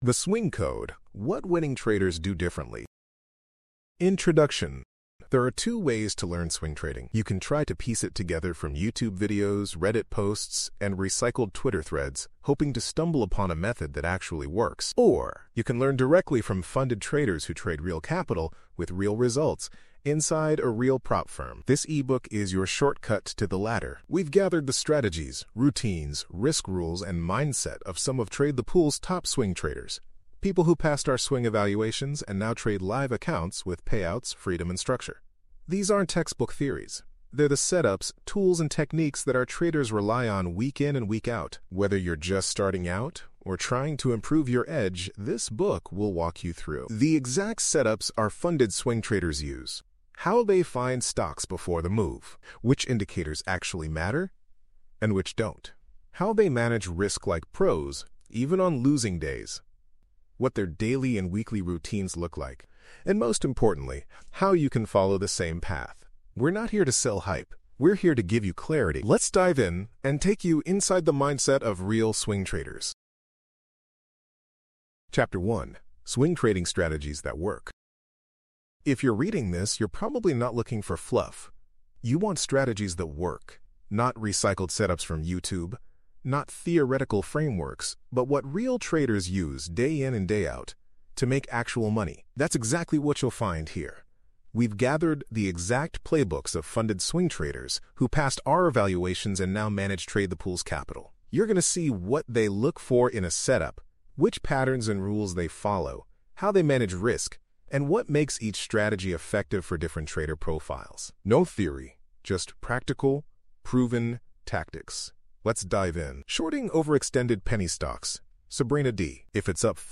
the-swing-code-audiobook.mp3